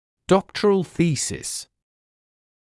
[‘dɔktərəl ‘θiːsɪs][‘доктэрэл ‘сиːсис]докторская диссертация